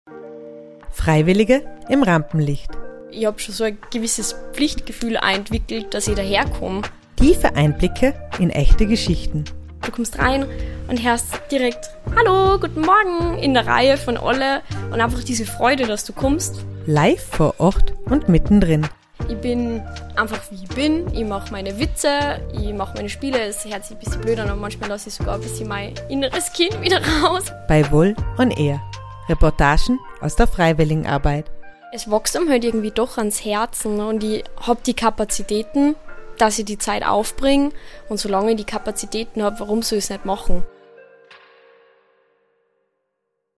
direkt vor Ort, mit viel Interaktion und spannenden Gesprächen.